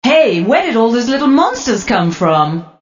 Tags: Techno clips sounds vocal odd funny wtf bbq